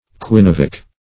Search Result for " quinovic" : The Collaborative International Dictionary of English v.0.48: Quinovic \Qui*no"vic\, a. (Chem.)
quinovic.mp3